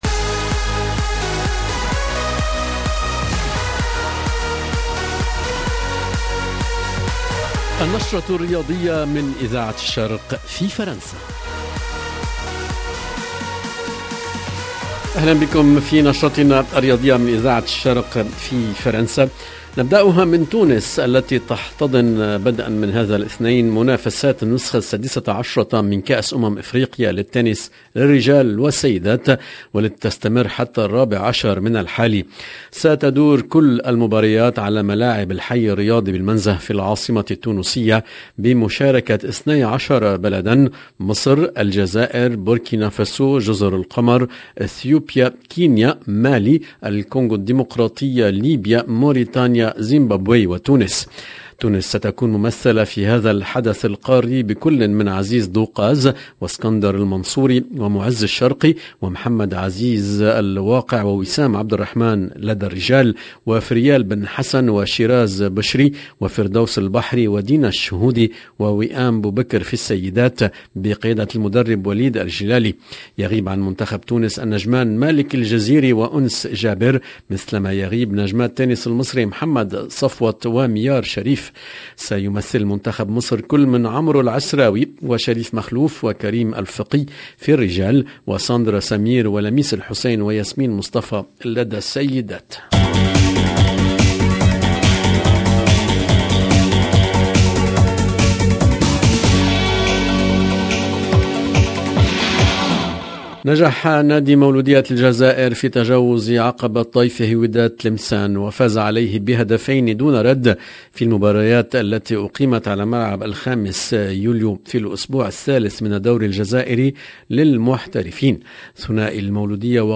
EDITION DU JOURNAL DU SPORT DU LUNDI 8/11/2021